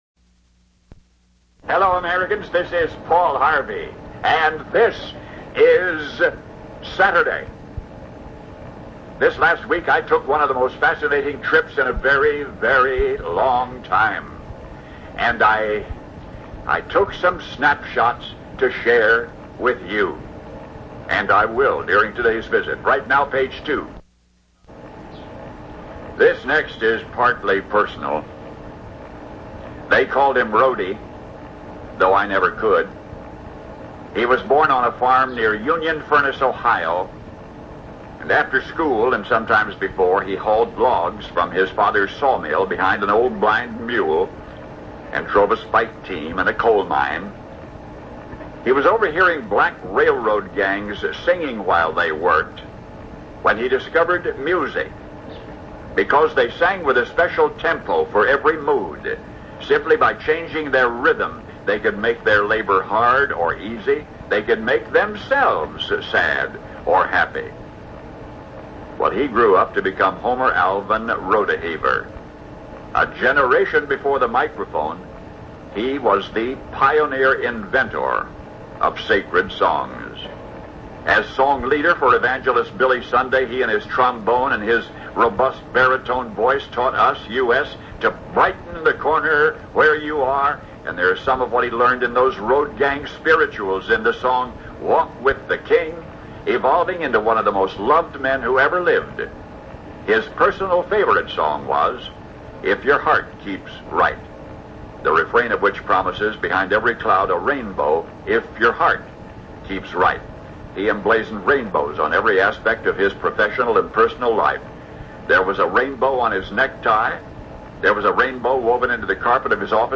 listen to the voice of the late Paul Harvey as he paints a word picture of the Roadheaver Boy’s Ranch.